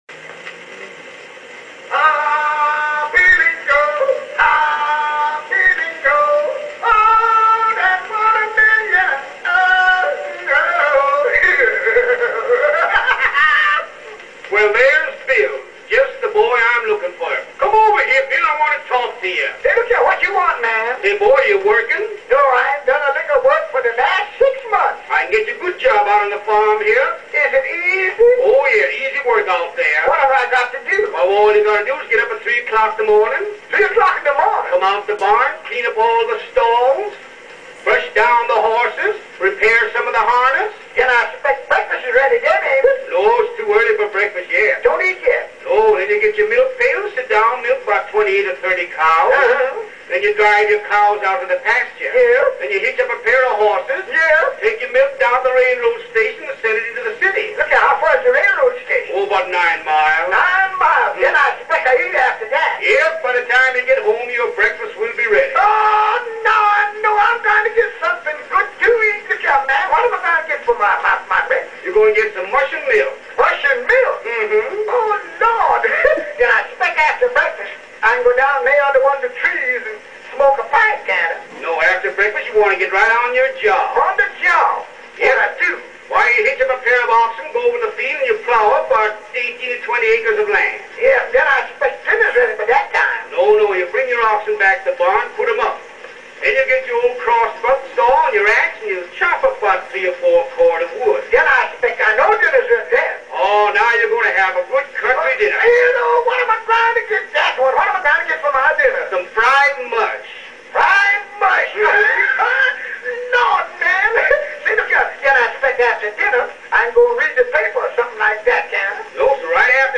The Music They had an Edison cylinder player and I played all of them.